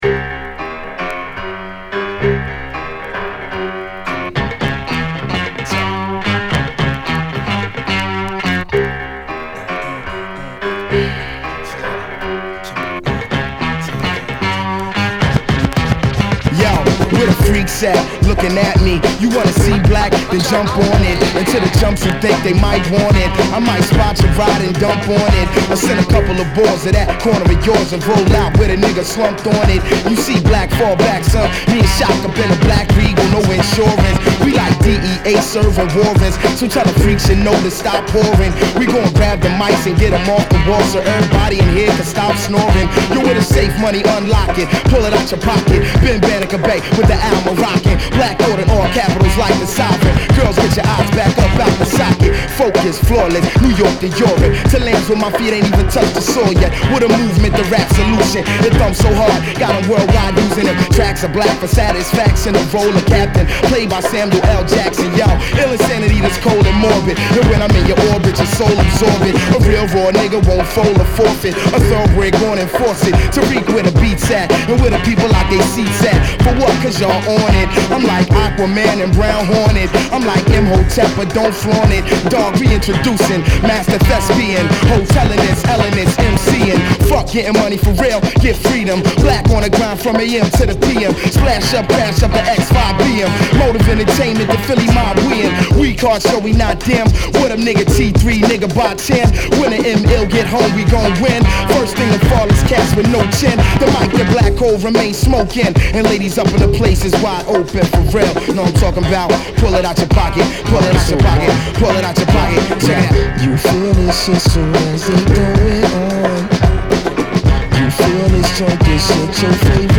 ホーム HIP HOP 00's 12' & LP R